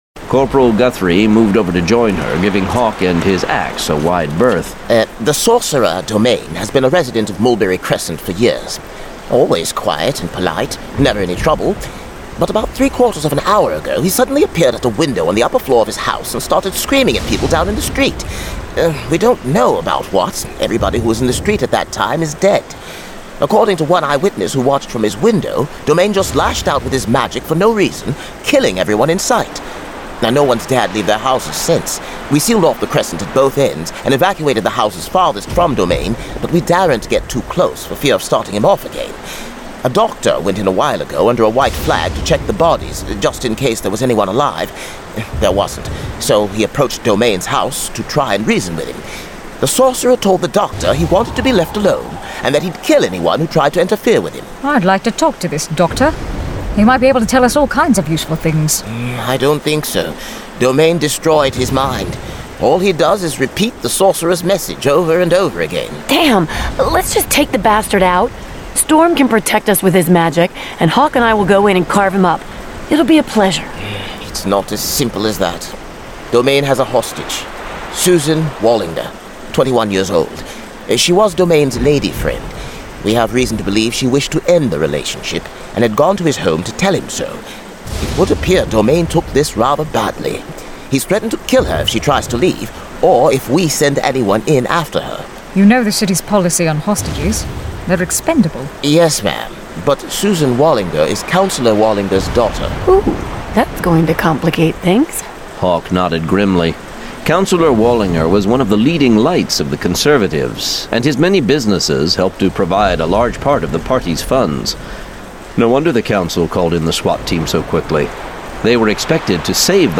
Forest Kingdom Saga: Hawk and Fisher 6: Bones of Haven [Dramatized Adaptation]